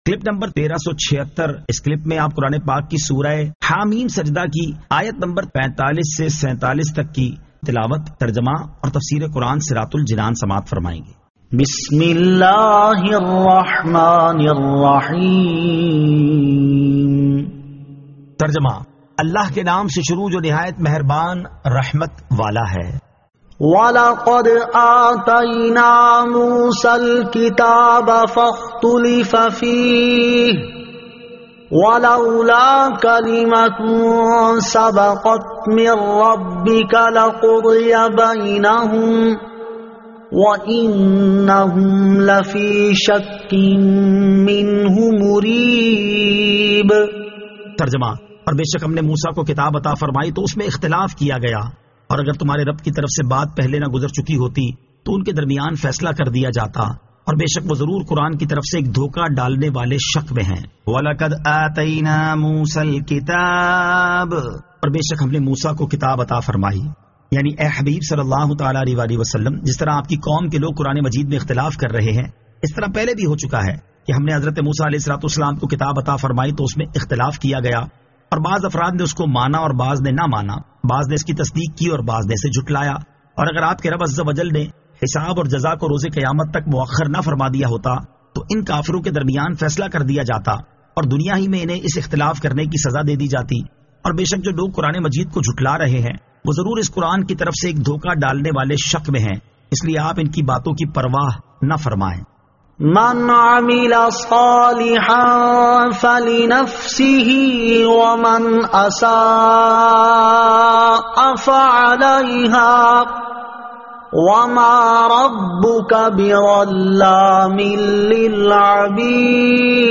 Surah Ha-Meem As-Sajdah 45 To 47 Tilawat , Tarjama , Tafseer
2023 MP3 MP4 MP4 Share سُوَّرۃُ حٰمٓ السَّجْدَۃِ آیت 45 تا 47 تلاوت ، ترجمہ ، تفسیر ۔